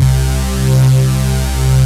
LAYER LEAD 2.wav